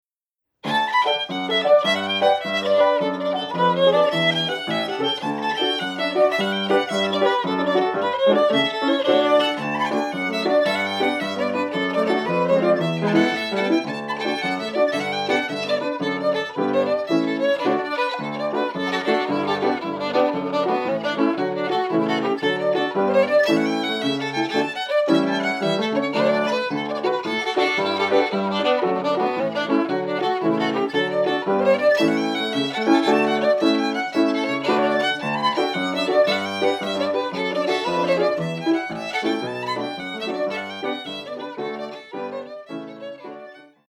where it is played as part of the following medley: